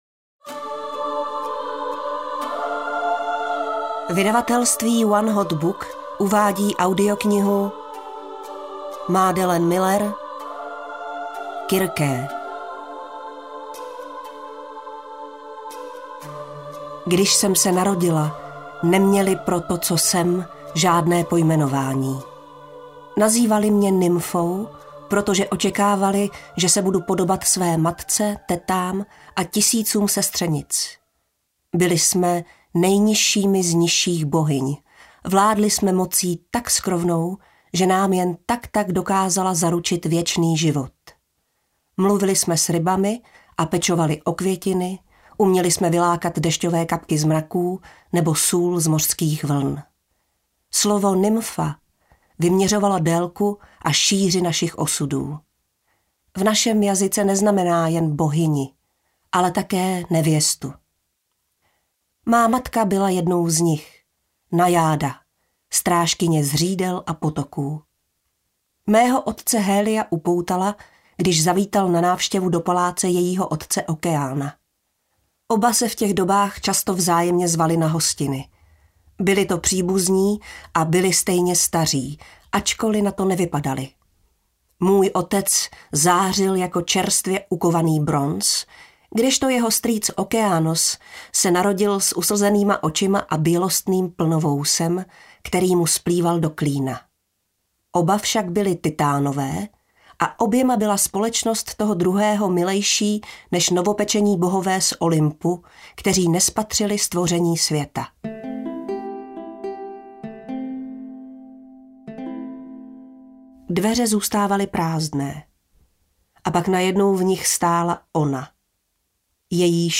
Kirké audiokniha
Ukázka z knihy
• InterpretPetra Špalková